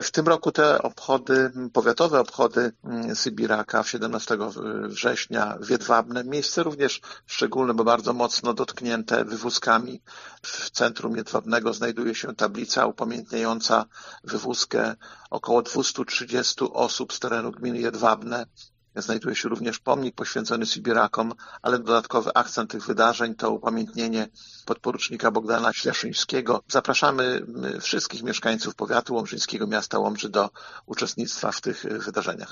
Miejsce wydarzenia słynie ze znaczących wydarzeń podsumowuje Starosta Łomżyński, Lech Marek Szabłowski: